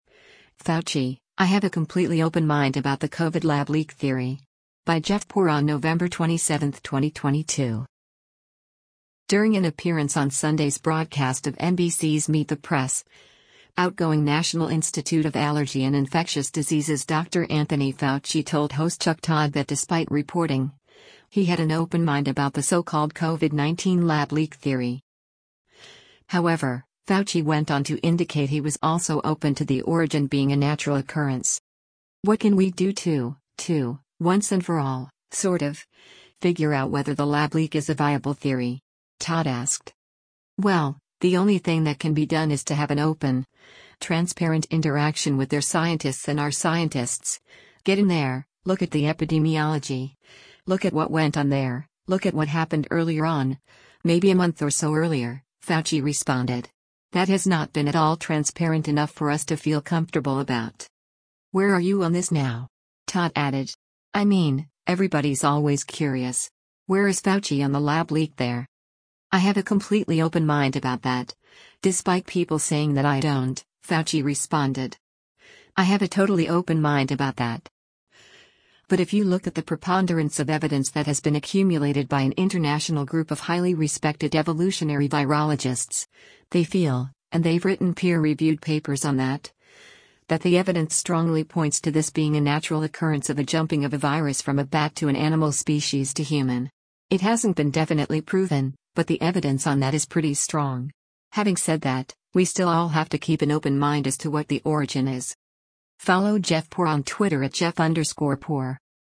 During an appearance on Sunday’s broadcast of NBC’s “Meet the Press,” outgoing National Institute of Allergy and Infectious Diseases Dr. Anthony Fauci told host Chuck Todd that despite reporting, he had an “open mind” about the so-called COVID-19 lab leak theory.